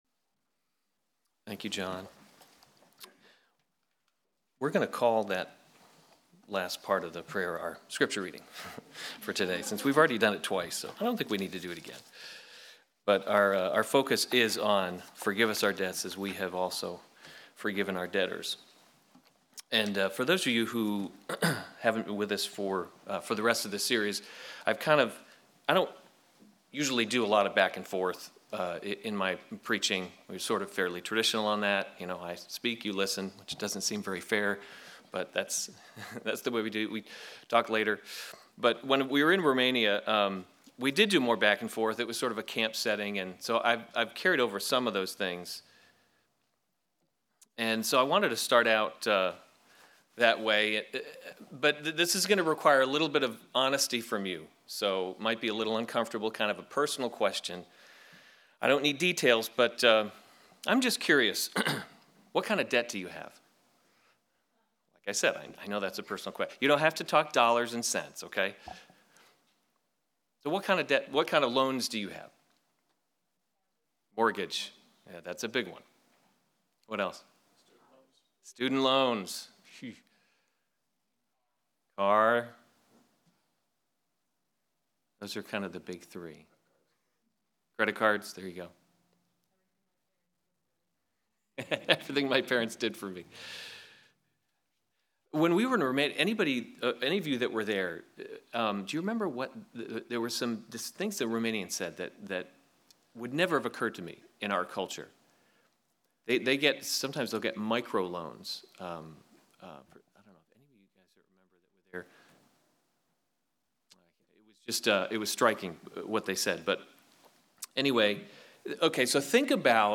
July 29, 2017 Lord’s Prayer: Kingdom Come series Weekly Sunday Service Save/Download this sermon Matthew 6:12 Other sermons from Matthew 12 and forgive us our debts, as we also have forgiven […]